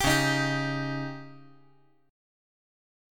Dbsus2b5 Chord
Listen to Dbsus2b5 strummed